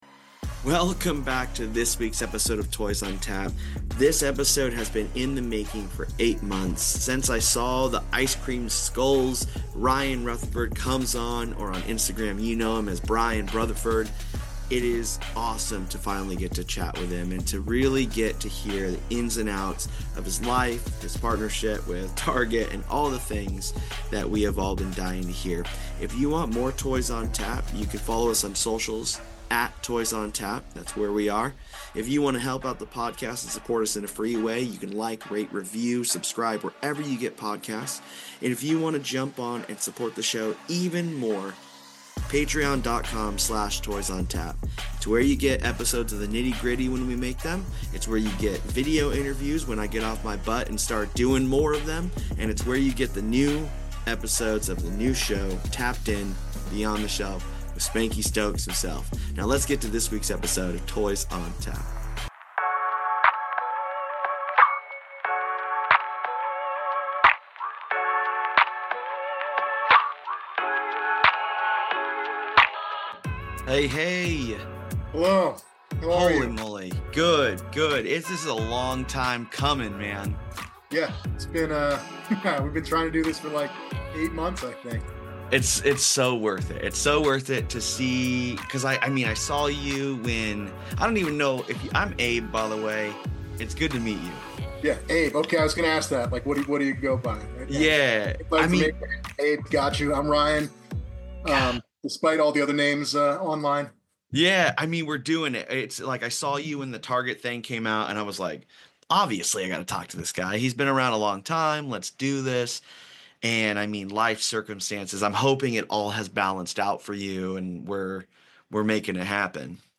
A podcast that interviews artists that make toys in the professional, independent, bootleg, and designer toy scenes!